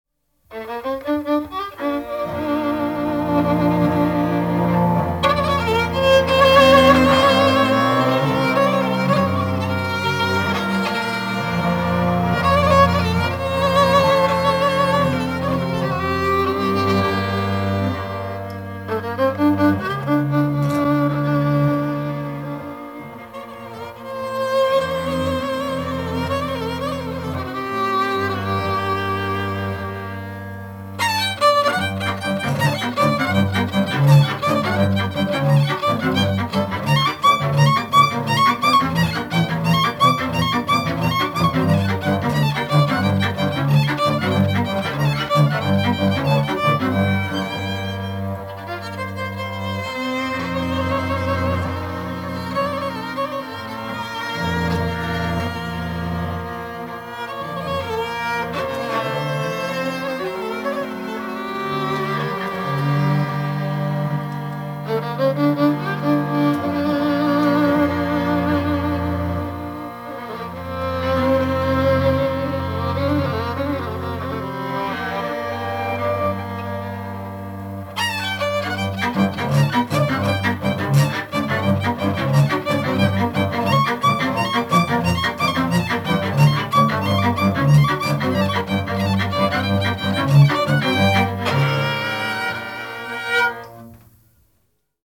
Hangszer Zenekar
Helység Bogártelke